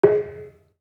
Kenong-dampend-G#3-f.wav